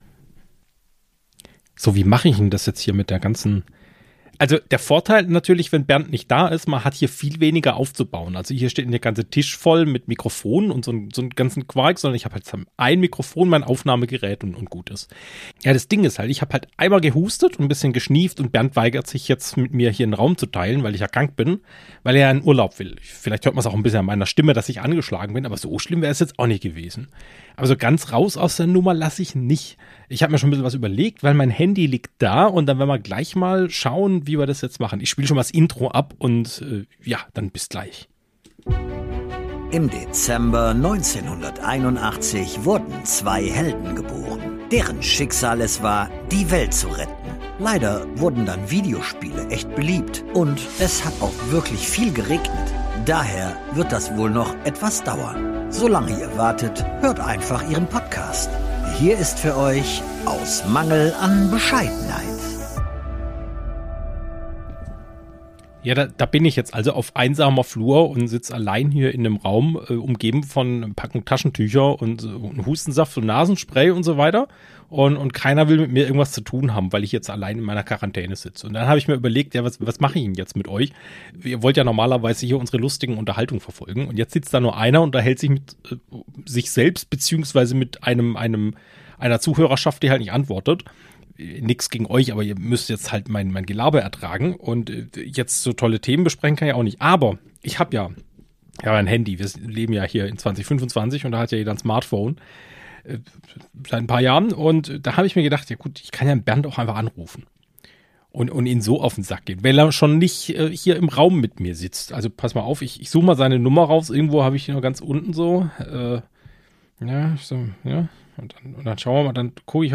Eine interessante und lustige Folge... per Telefon.